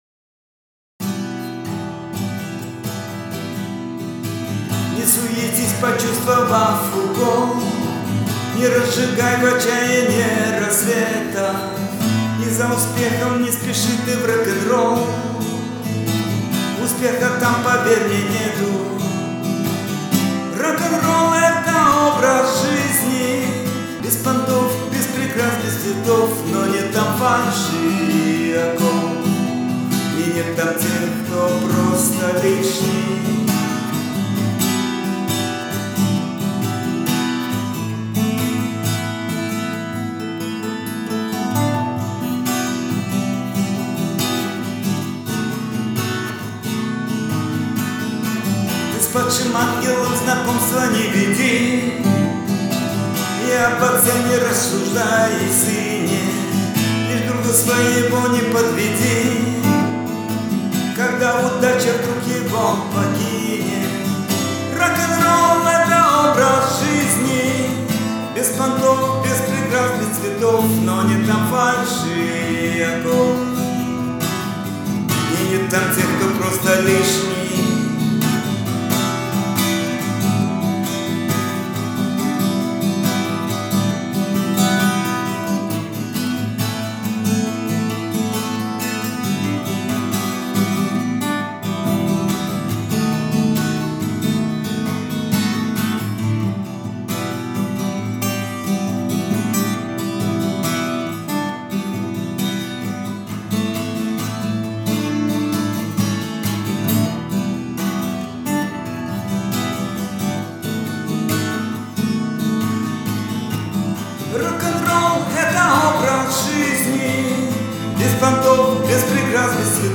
гитара